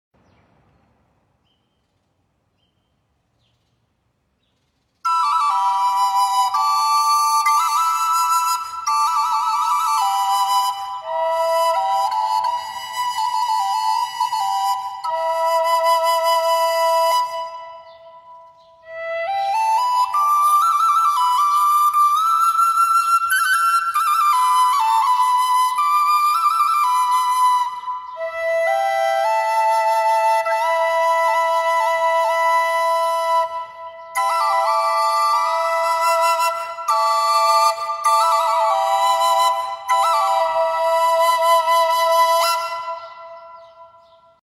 Mini Flauta Tripla (Em)
• Afinação: Em (Mi menor)
• Tipo: Tripla, proporcionando uma sonoridade complexa e harmônica
Seus tons profundos e harmônicos criam um ambiente de paz e equilíbrio.
• Qualidade Sonora: A flauta produz sons ricos e contínuos, capturando a essência da música nativa.
Mini-Flauta-Tripla-Em.mp3